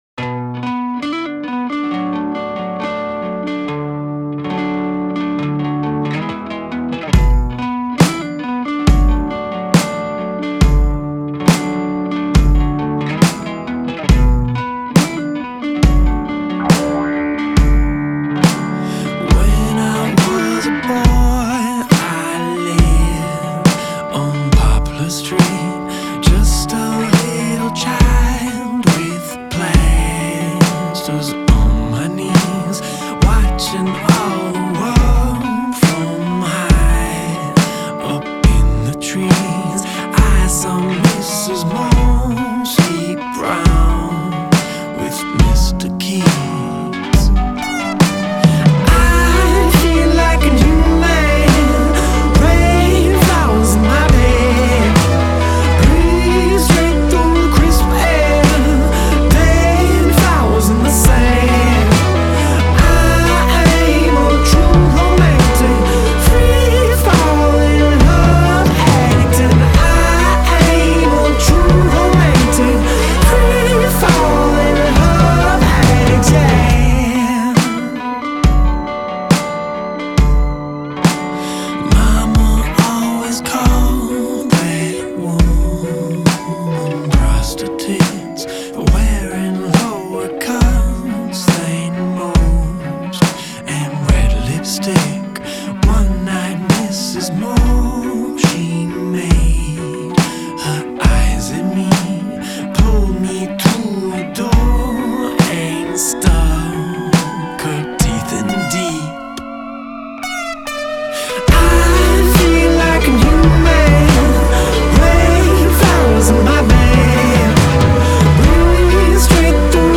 Жанр: Indie.